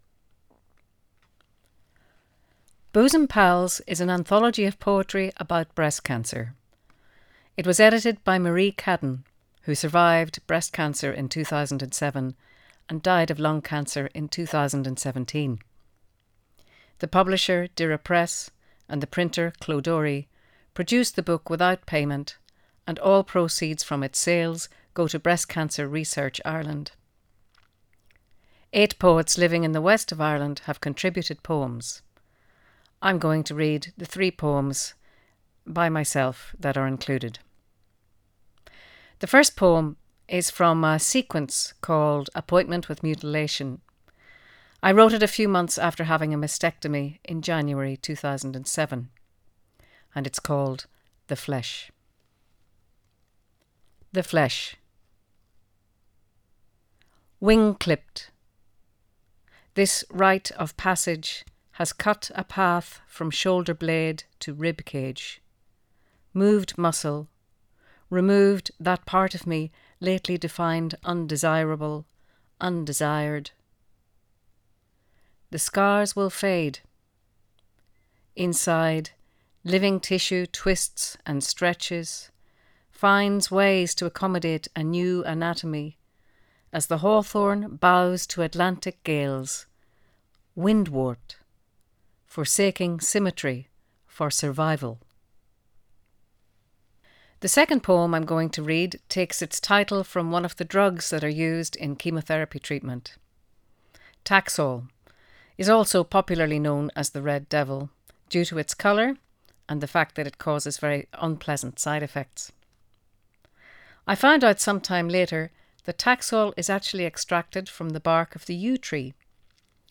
In our Writers’ Corner you will find videos and podcasts of poets, fiction and non- fiction writers reading from their own work on the themes of medicine, the environment, personal and planetary degradation and regeneration.